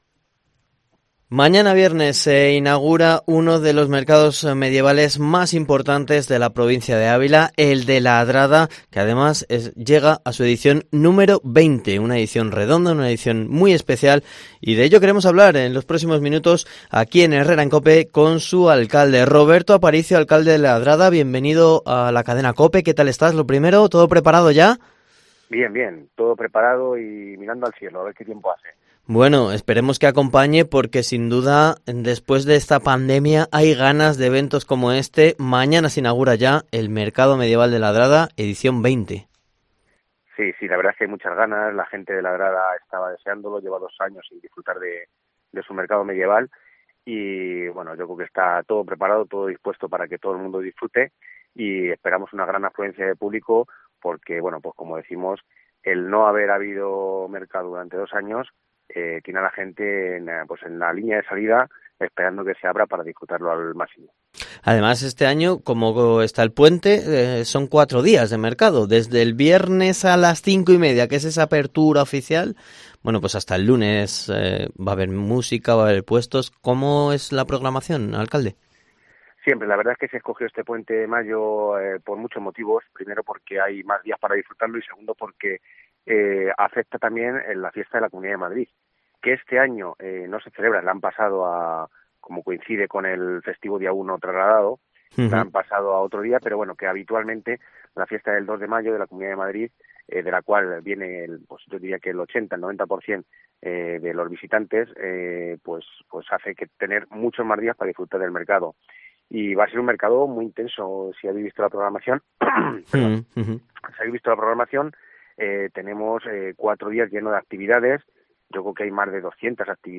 ENTREVISTA
ENTREVISTA en Herrera en COPE con el alcalde de La Adrada, Roberto Aparicio